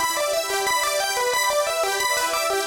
Index of /musicradar/shimmer-and-sparkle-samples/90bpm
SaS_Arp02_90-E.wav